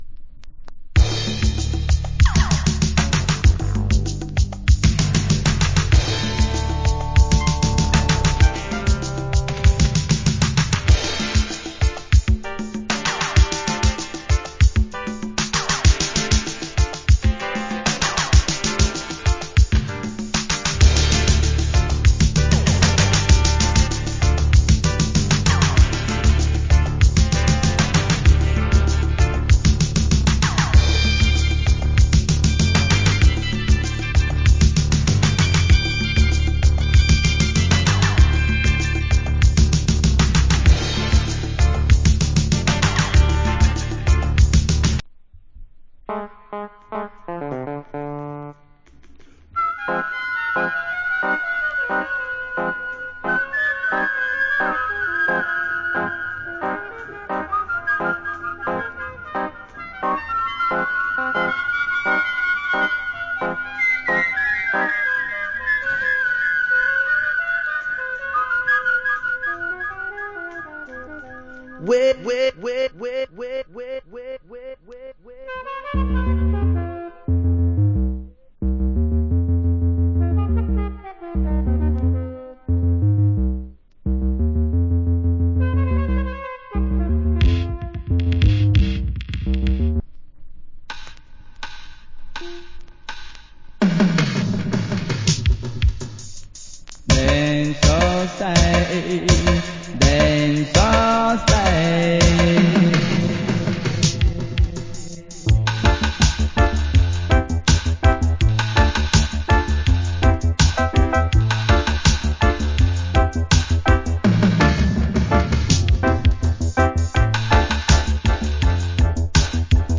80's Dub. 1987 In UK.